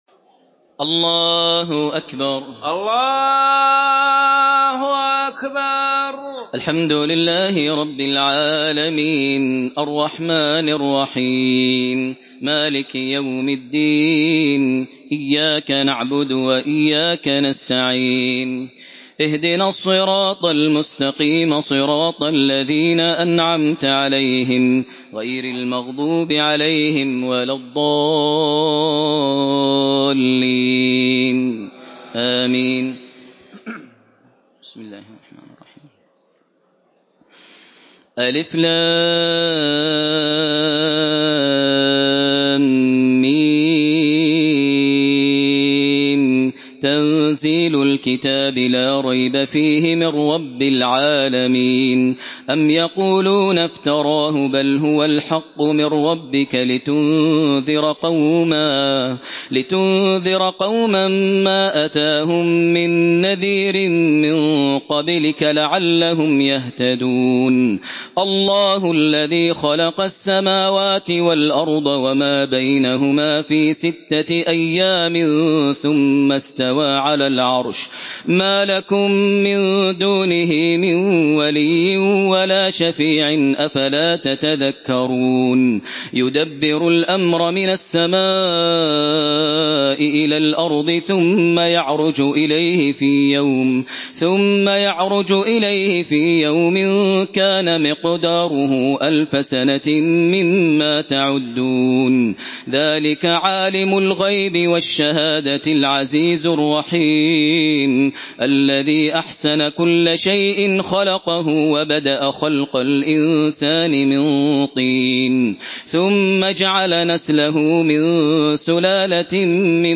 İndir Secde Suresi Sesi Makkah Taraweeh 1432
Murattal